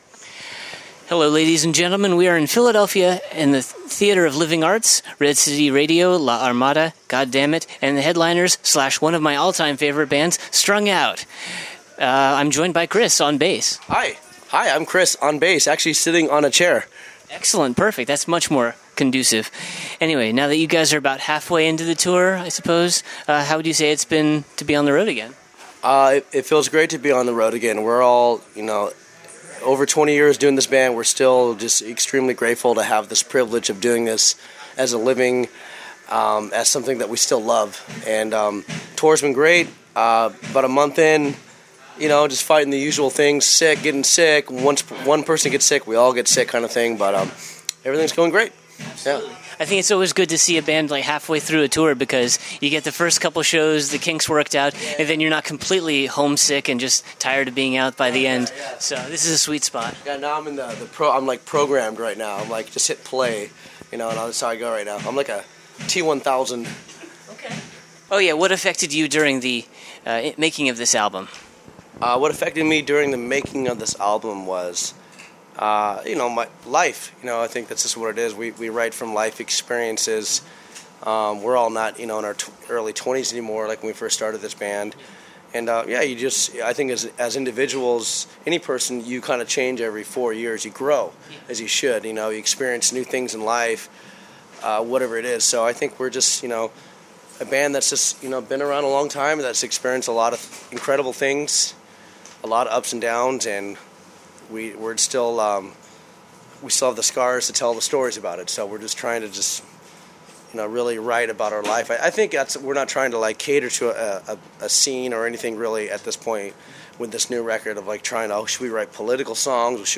57-interview-strung-out.mp3